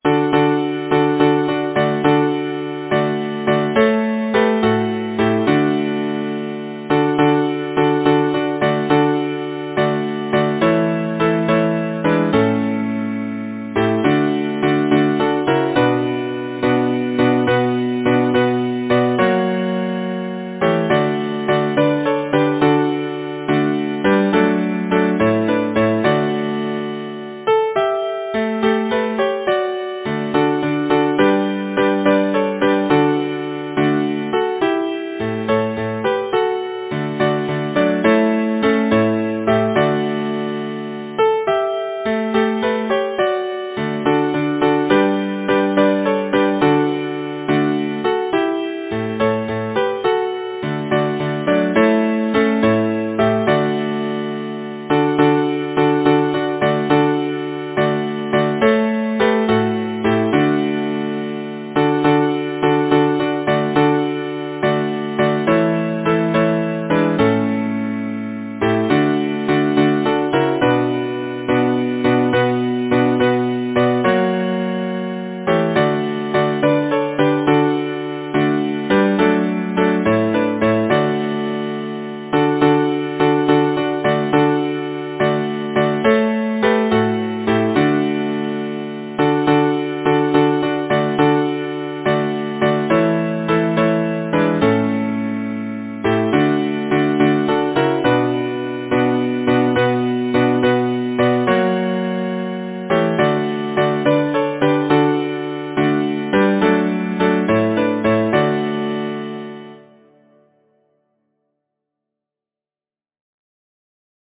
Title: Trip lightly Composer: Edwin T. Pound Lyricist: Number of voices: 4vv Voicing: SATB Genre: Secular, Partsong
Language: English Instruments: A cappella